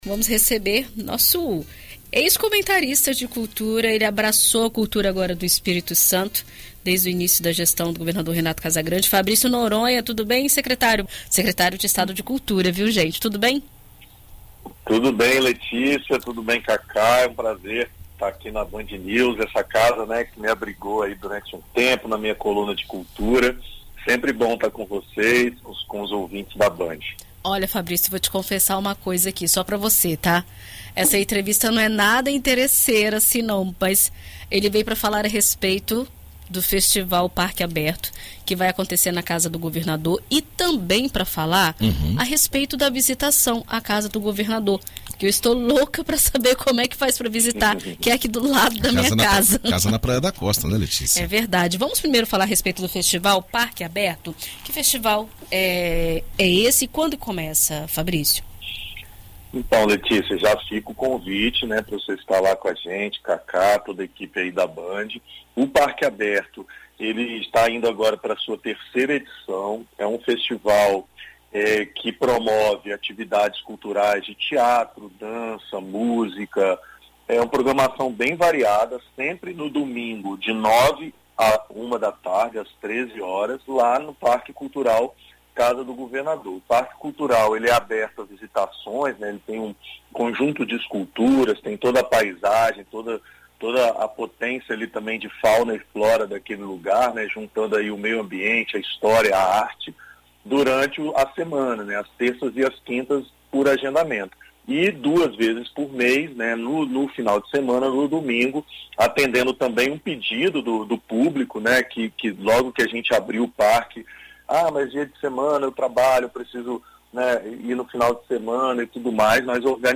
Em entrevista à BandNews FM Espírito Santo nesta quinta-feira (04), o secretário de Estado da Cultura, Fabrício Noronha, conversa sobre o que será realizado no parque e explica como conseguir os ingressos, que estão disponíveis pela plataforma Sympla.